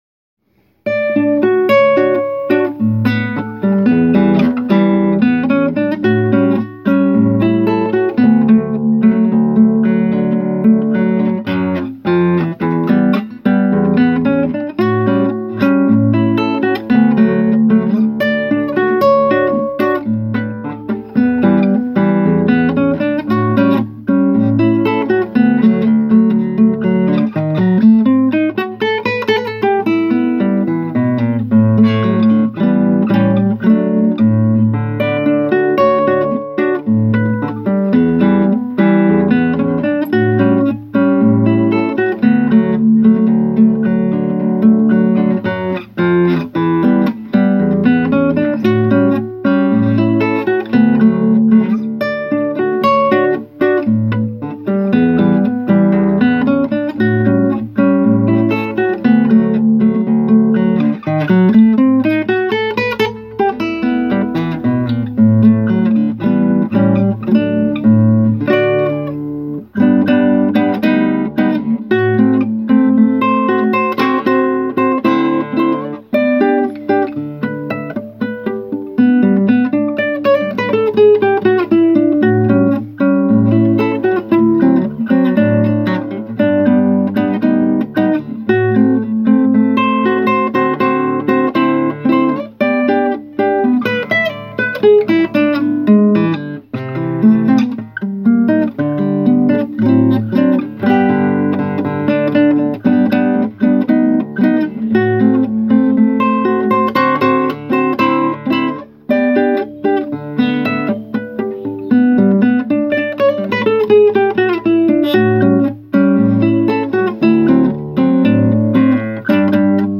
Som de carrilhoes
som_de_carrilhoes.mp3